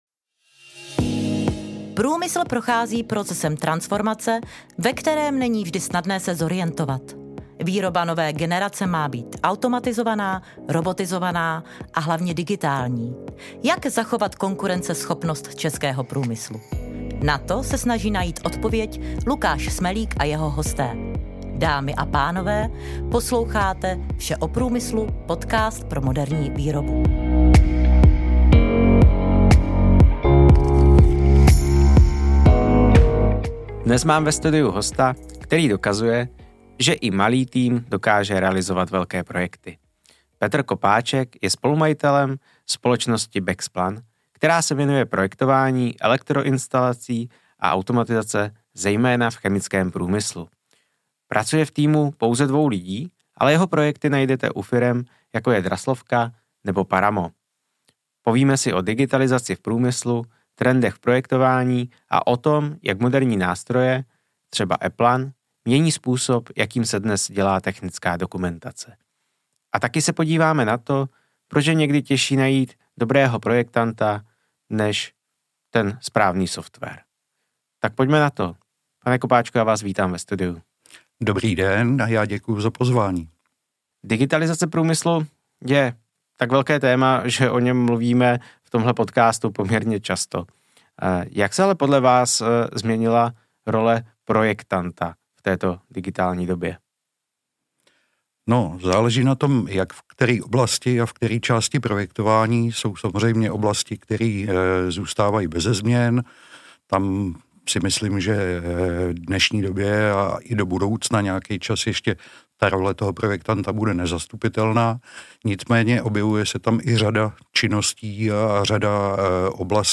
Dnes máme ve studiu hosta, který dokazuje, že i malý tým dokáže realizovat velké projekty.